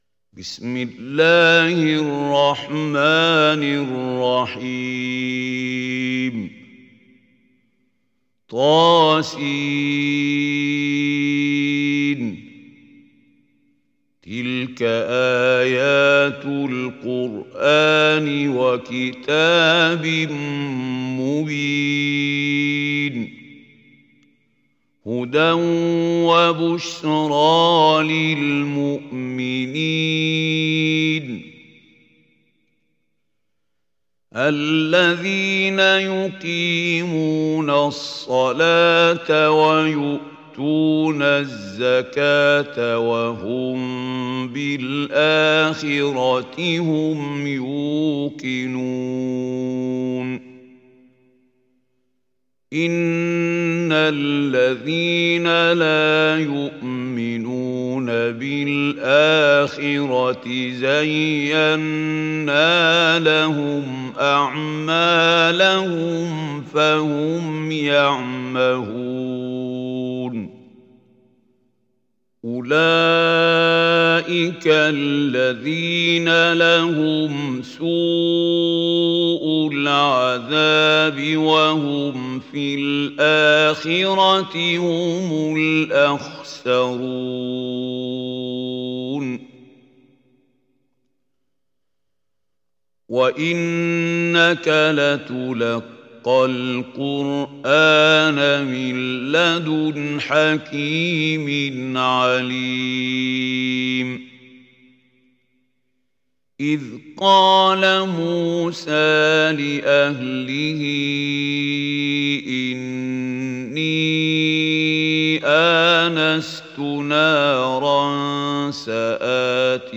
حفص از عاصم